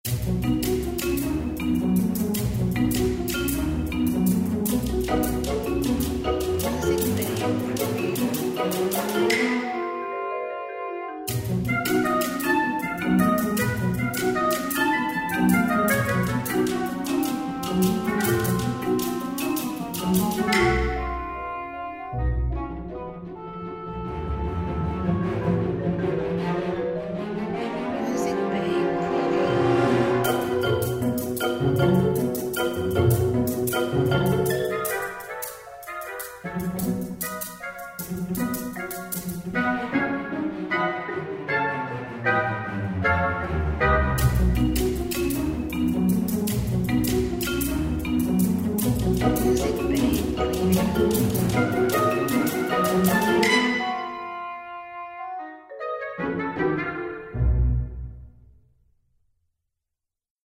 Positive background music.
Tempo (BPM): 95